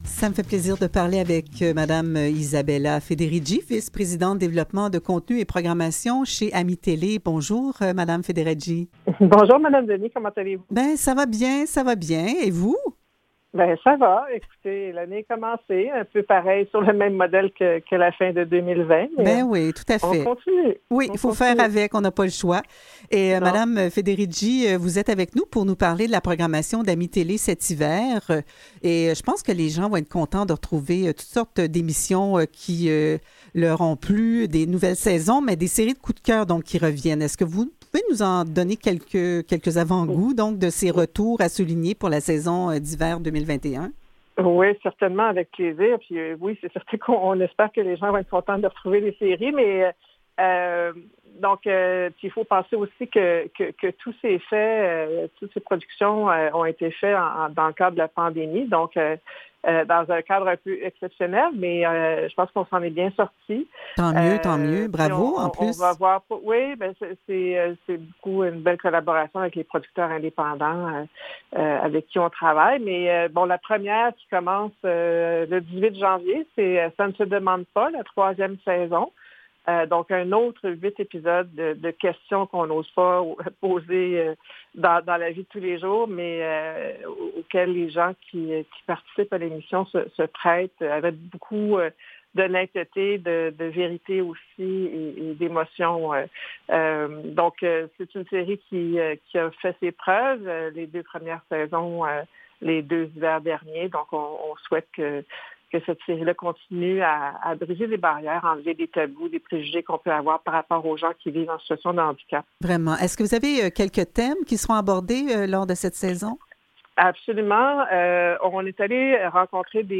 Revue de presse et entrevues du 12 janvier 2021